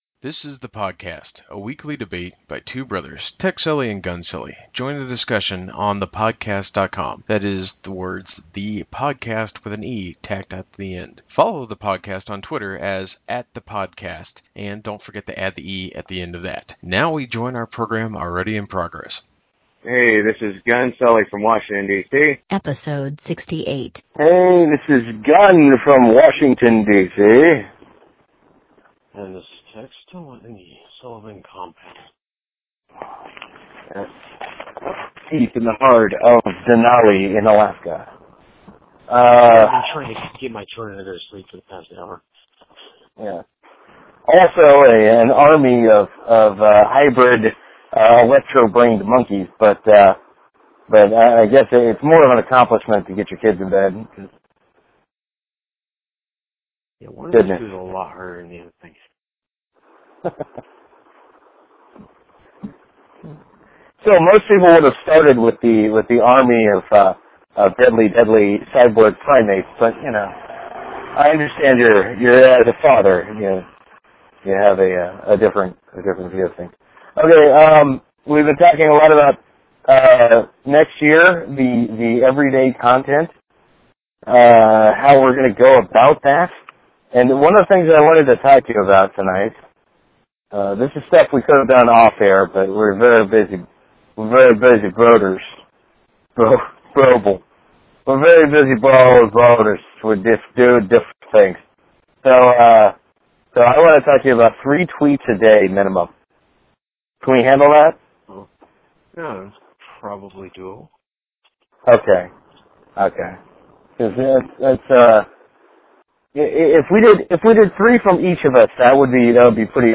Schedules are crazy, so we traditionally ended up with just having a Google Hangout that we record as a Wiretap Wednesday.